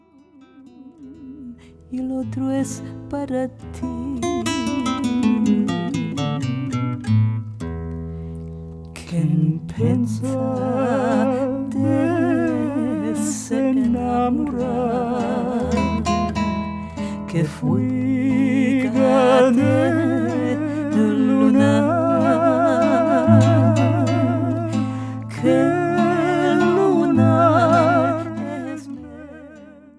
A vibrant, alive collection of Ladino duets
Folk